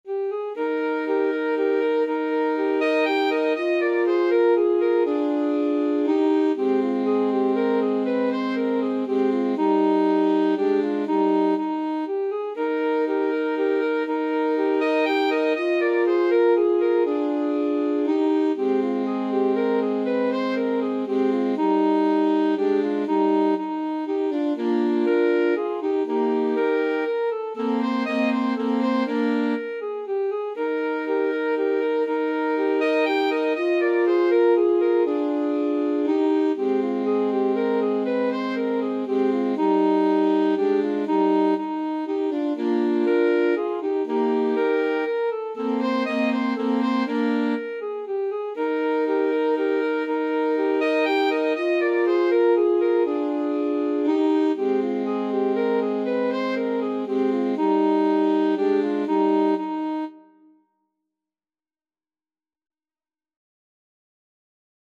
3/4 (View more 3/4 Music)
Alto Sax Trio  (View more Easy Alto Sax Trio Music)
Classical (View more Classical Alto Sax Trio Music)